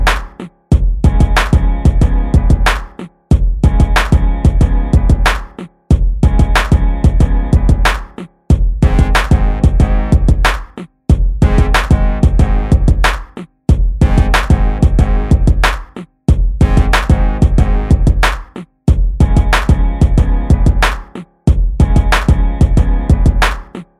no Backing Vocals R'n'B / Hip Hop 3:28 Buy £1.50